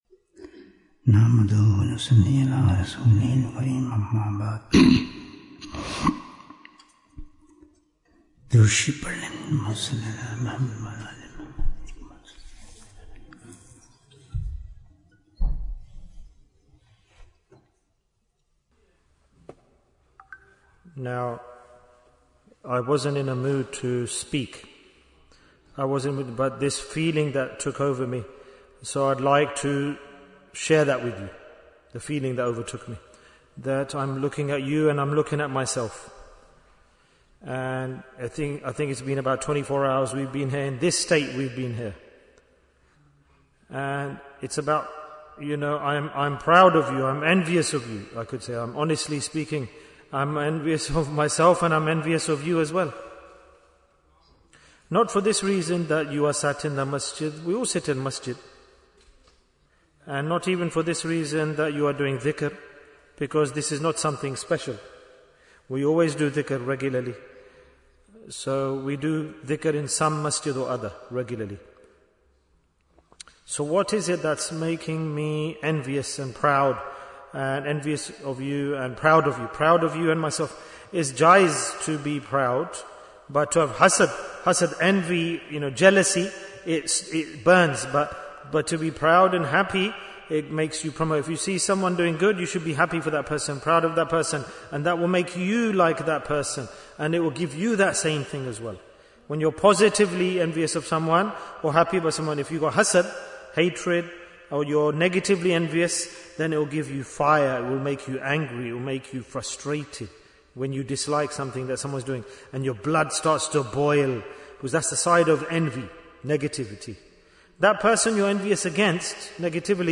Bayan After Magrib in Manchester Bayan, 94 minutes12th April, 2026